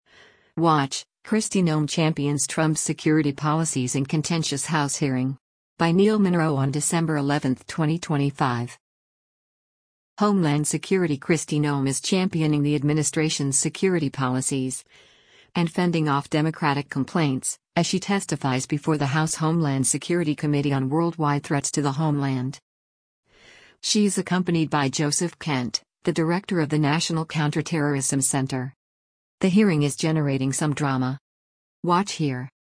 Homeland Security Kristi Noem is championing the administration’s security policies — and fending off Democratic complaints — as she testifies before the House Homeland Security Committee on “Worldwide Threats to the Homeland.”